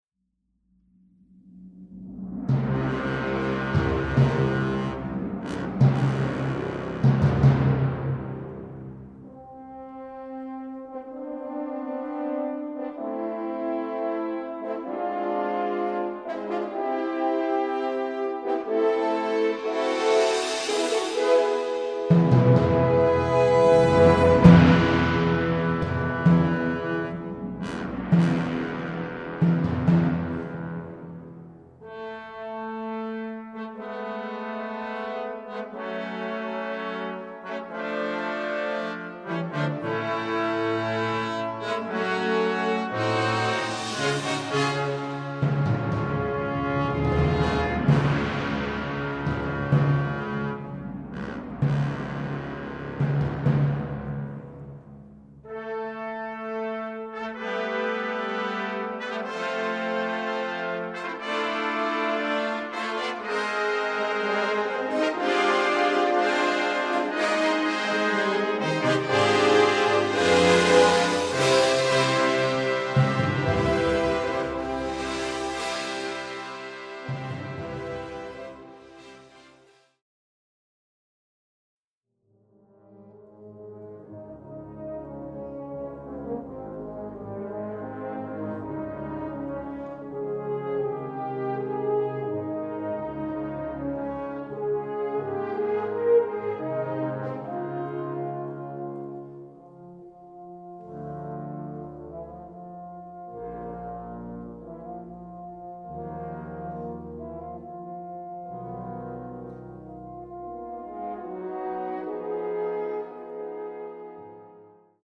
Répertoire pour Brass Band